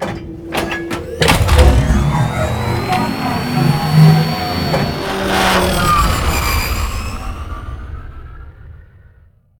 vehiclelaunch.ogg